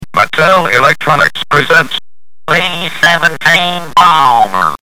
A friend of mine had that game and the expensive Speech Synthesis module, which allowed many of its games to “talk. B-17 Bomber was one such game. Thanks to the Internet, you can listen to the introductory sound from that game by clicking